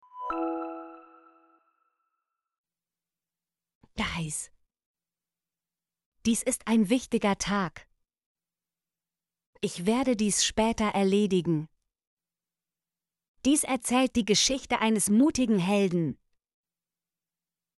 dies - Example Sentences & Pronunciation, German Frequency List